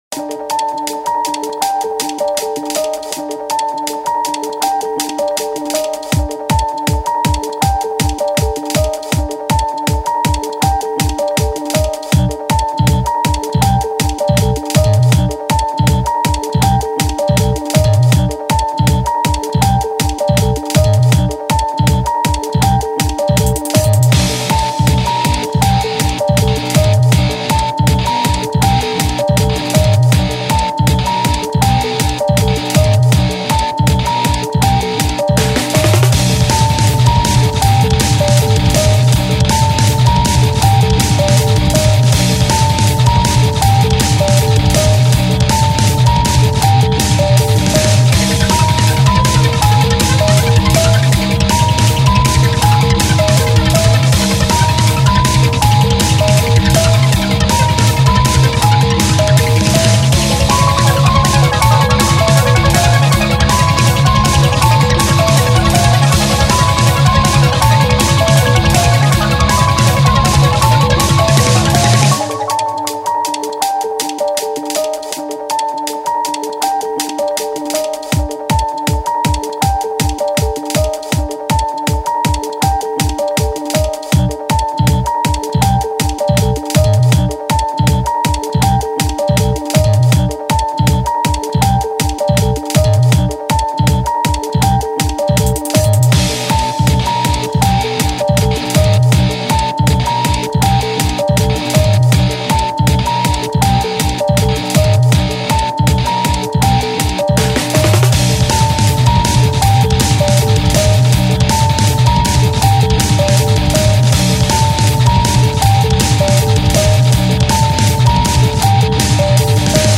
ジャンルロック、エレクトリックミュージック
BPM１６０
使用楽器シンセサイザー(リード、マレット)
解説エレクトリックロックのBGMです。
怪しい研究所を題材にしておりますが、推理中や不穏な雰囲気のシーン、ダンジョンなど幅広く使えます。